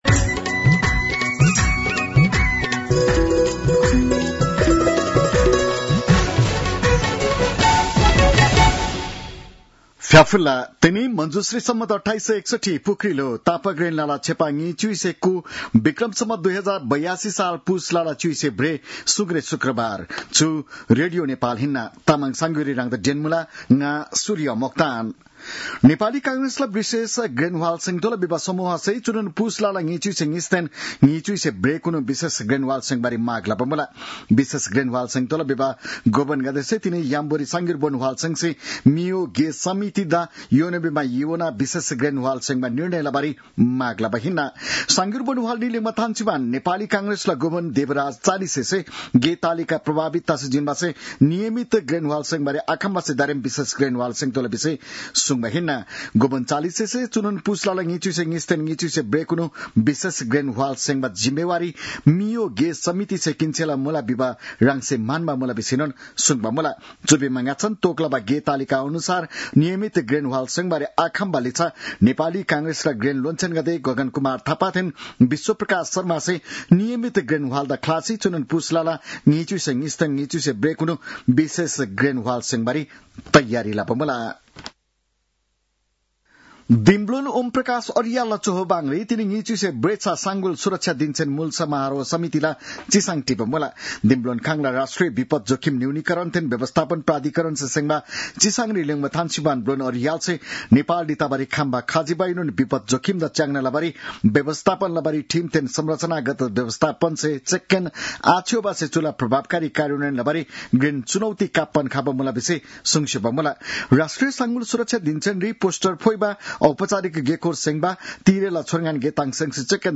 तामाङ भाषाको समाचार : १८ पुष , २०८२